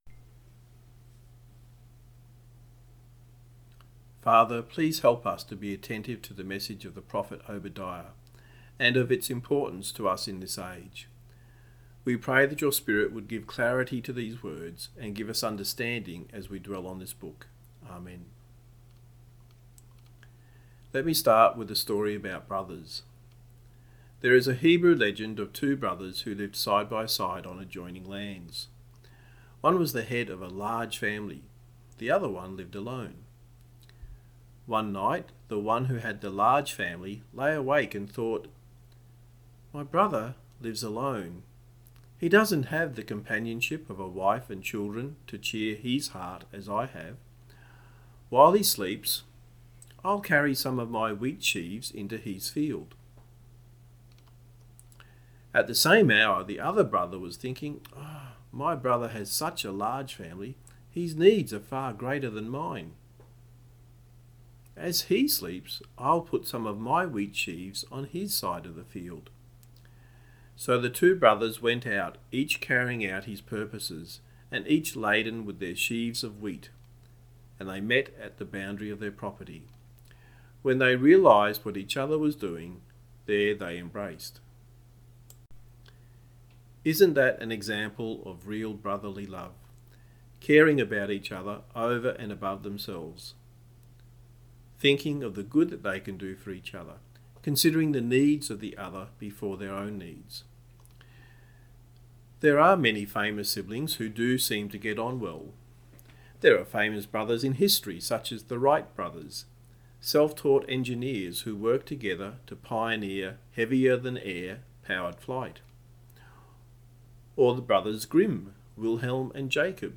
A sermon on the book of Obadiah
Service Type: Sunday Morning